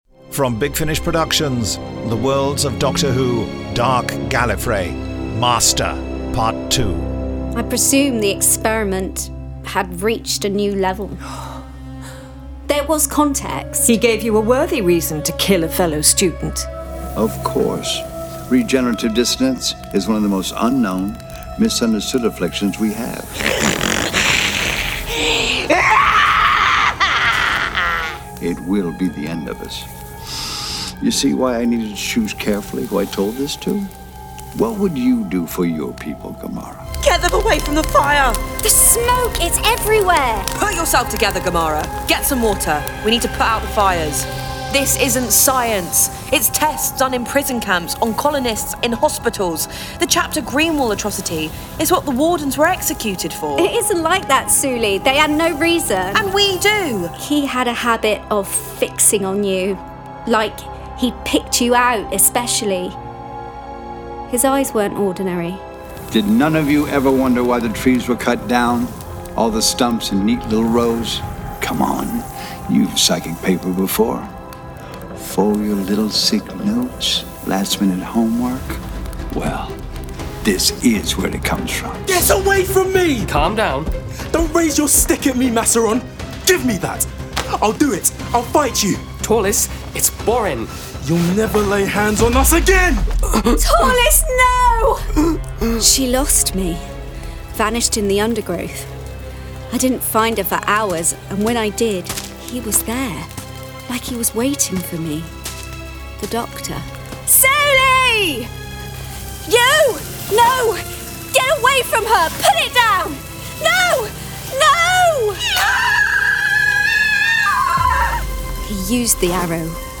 Starring Eric Roberts